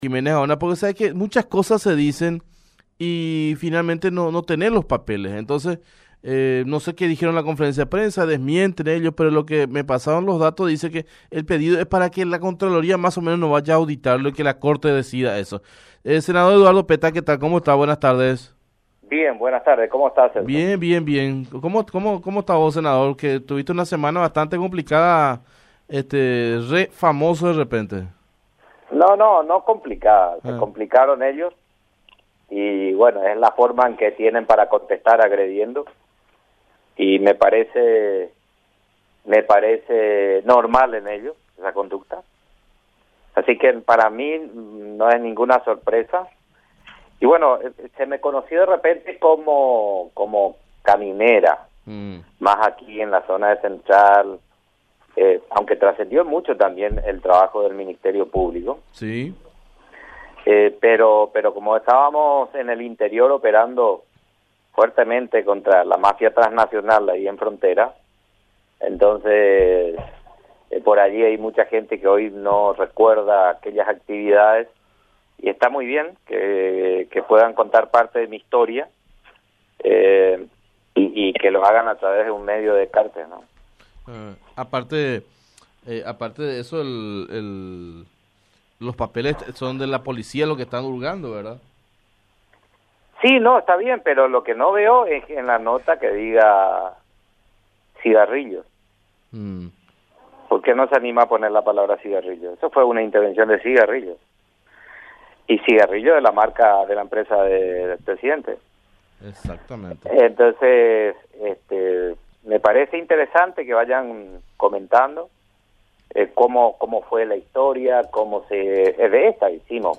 017-SENADOR-PETTA-EDITADO.mp3